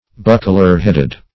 \Buc"kler-head`ed\
buckler-headed.mp3